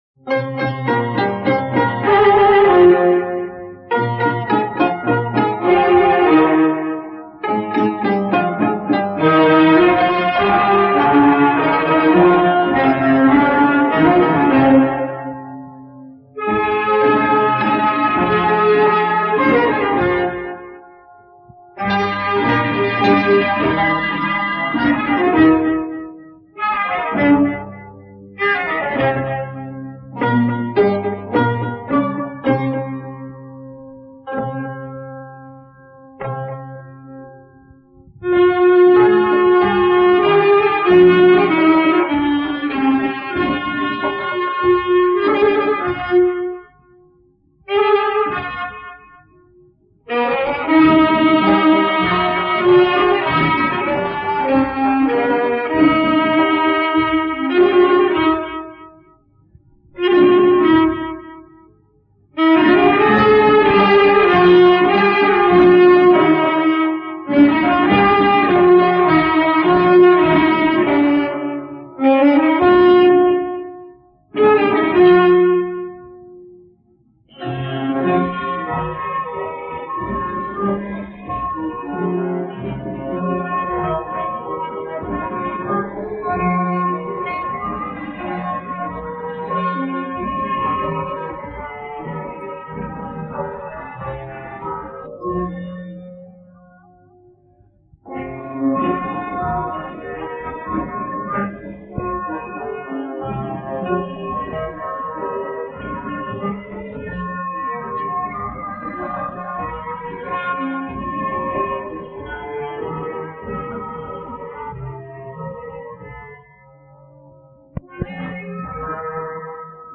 + آهنگ بیکلام تصنیف